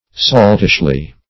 -- Salt"ish*ly , adv.